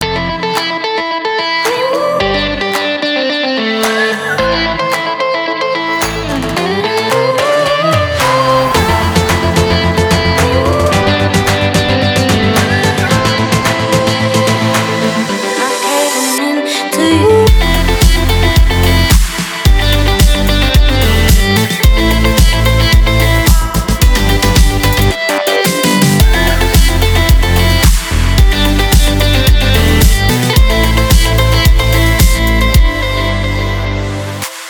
• Качество: 320, Stereo
гитара
веселые
Midtempo
инструментальные